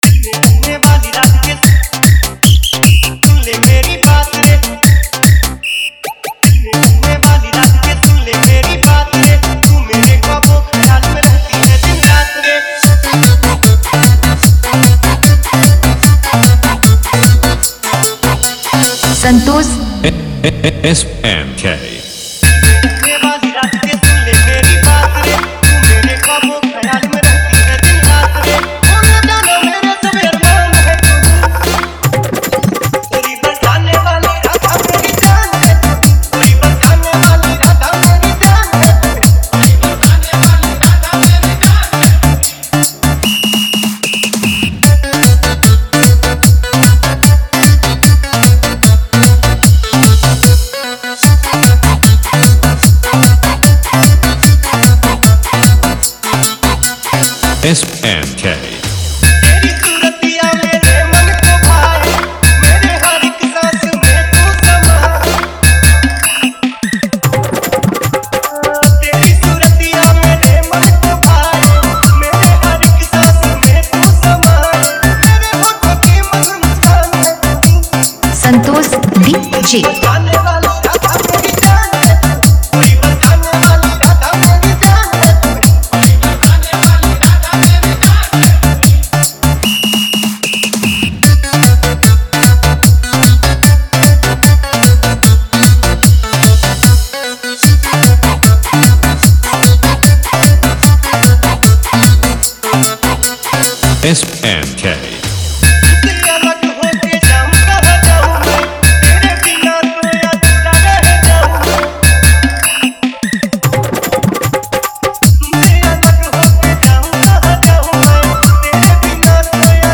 Radha Krishna dj mix
Bhakti dance dj mix
Krishna bhajan dance remix